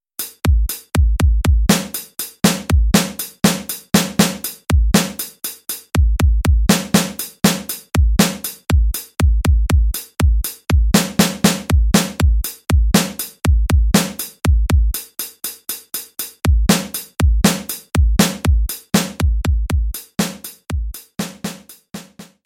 Im folgenden Beispiel wird aus dem Array [:bd_haus, :drum_snare_hard, :drum_cymbal_closed] bei jedem Schleifendurchgang eines der drei Samples zufällig ausgewählt und abgespielt: